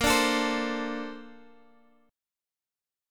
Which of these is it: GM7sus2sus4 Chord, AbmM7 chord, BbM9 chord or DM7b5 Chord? BbM9 chord